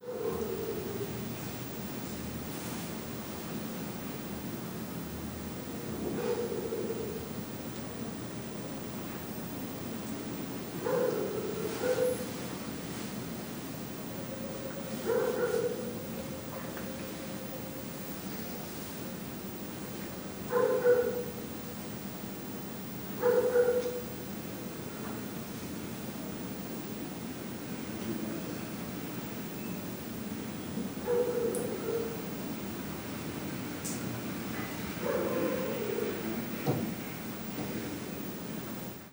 Ambiente interior habitación con un perro al fondo
ladrido
Sonidos: Animales
Sonidos: Hogar